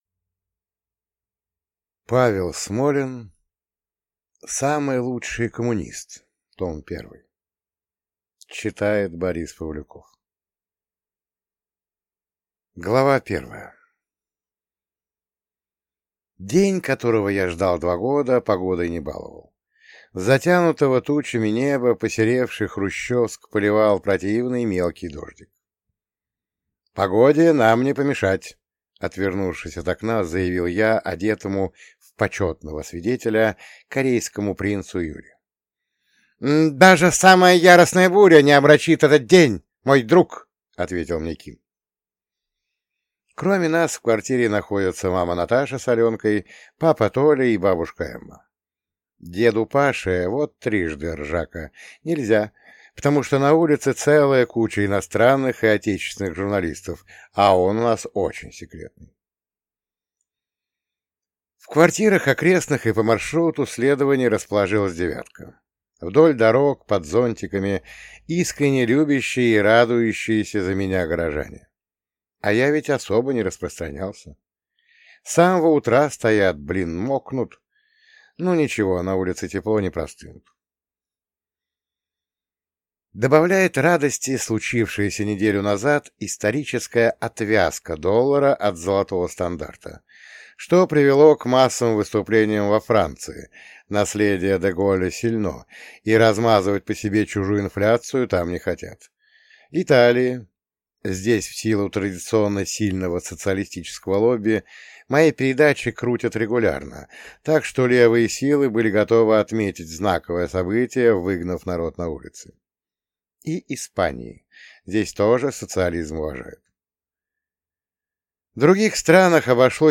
Самый лучший коммунист. Том 1 (слушать аудиокнигу бесплатно) - автор Павел Смолин